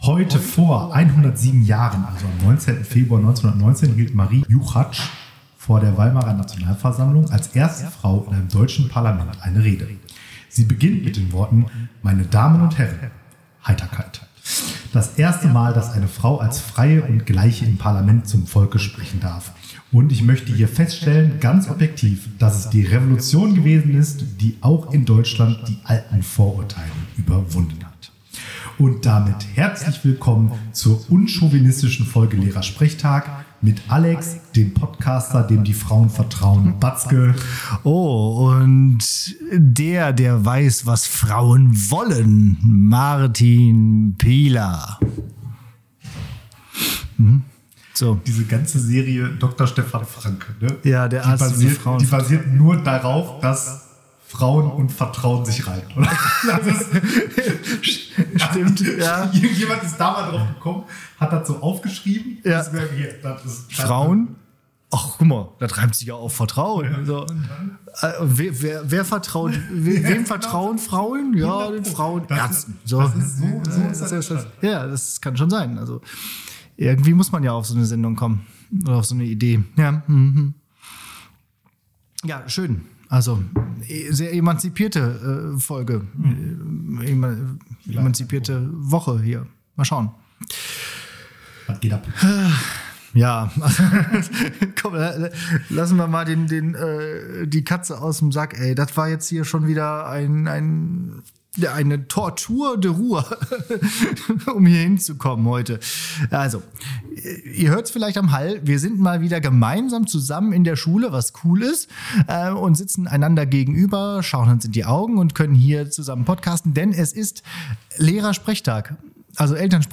Beschreibung vor 1 Monat Normalerweise klingt der Sound, wenn sich die Studienräte in der Schule gegenübersitzen, etwas hallend, weil Klassenräume nun einmal hoch und groß sind.